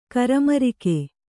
♪ karamarike